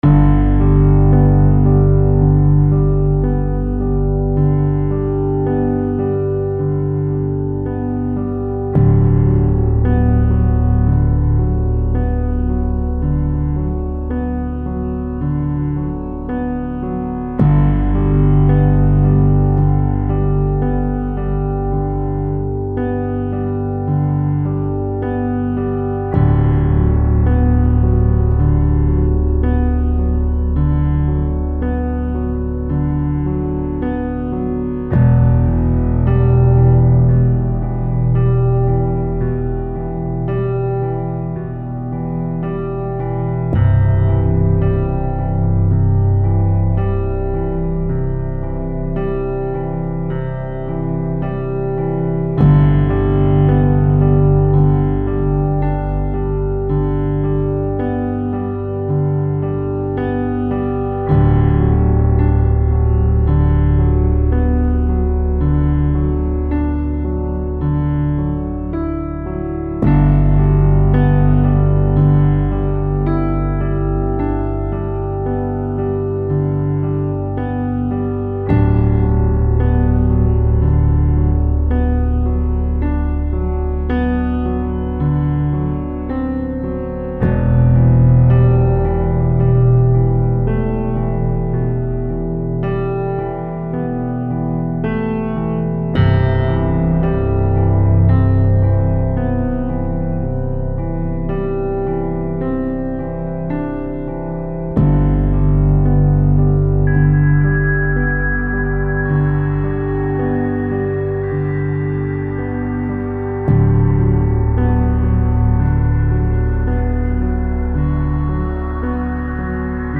Style Style EDM/Electronic, Soundtrack
Mood Mood Intense, Relaxed
Featured Featured Cello, Piano, Strings +2 more
BPM BPM 55